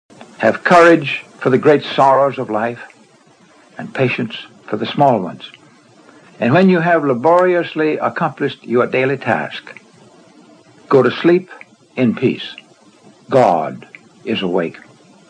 Click here to hear Red Barber read the above quote